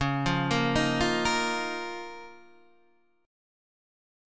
Db6add9 chord